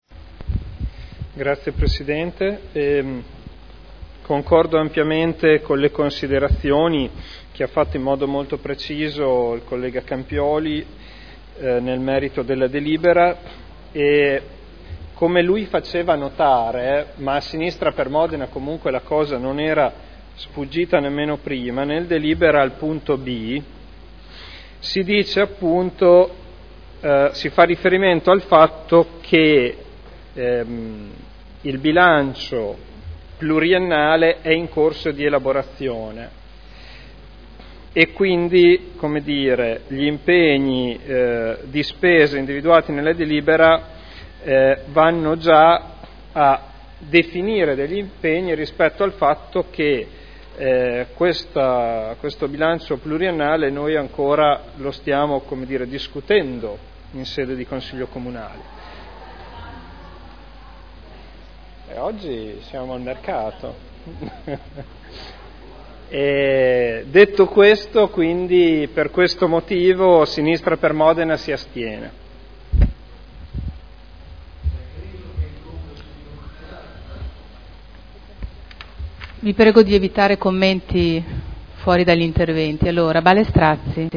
Federico Ricci — Sito Audio Consiglio Comunale
Dichiarazione di voto. Linee di indirizzo per l’affidamento dei servizi ausiliari – assistenziali del nido Cipì